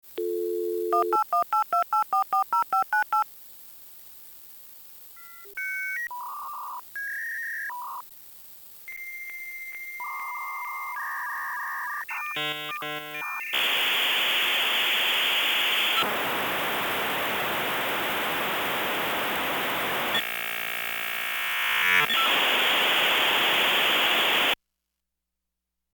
dial-up-modem-01.mp3